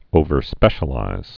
(ōvər-spĕshə-līz)